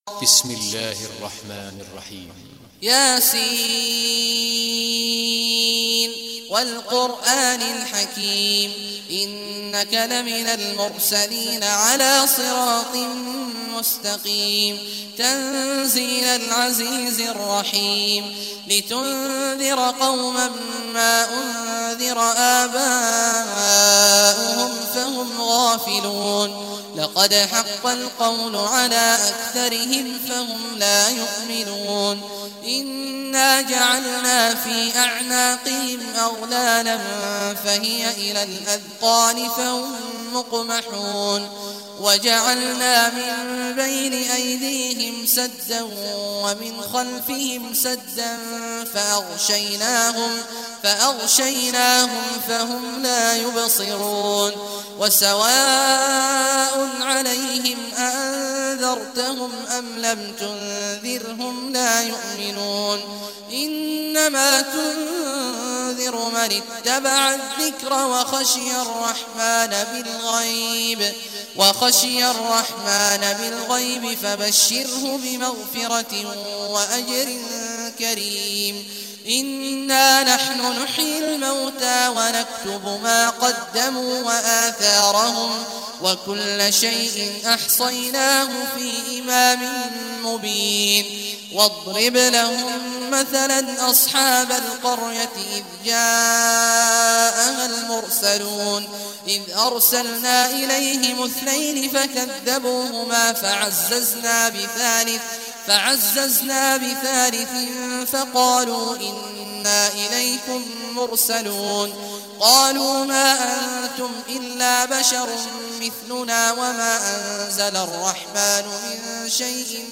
Surah Yaseen Recitation by Sheikh Awad al Juhanay
Surah Yaseen, listen or play online mp3 tilawat / recitation in Arabic in the beautiful voice of Sheikh Abdullah Awad al Juhany.